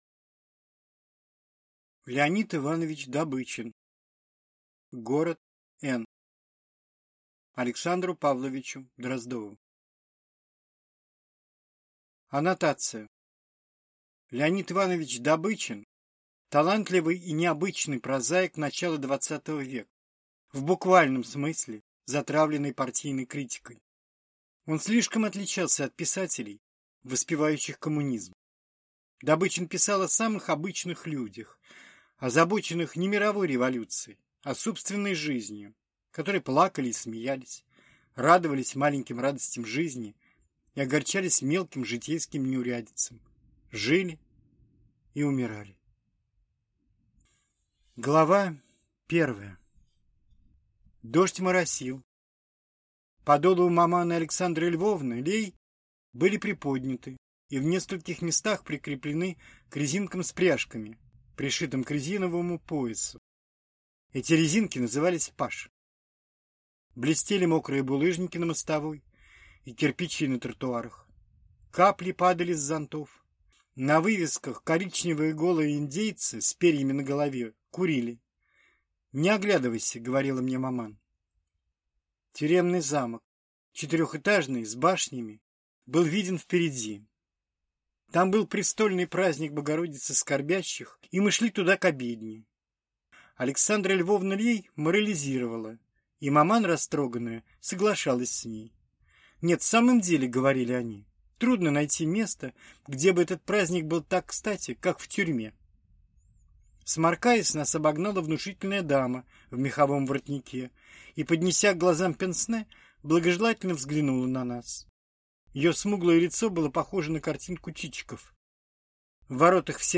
Аудиокнига Город Эн | Библиотека аудиокниг